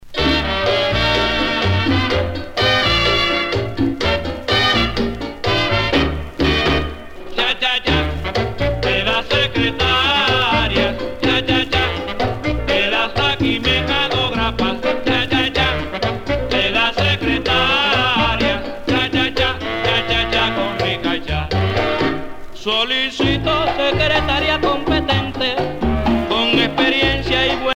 danse : cha cha cha
Pièce musicale éditée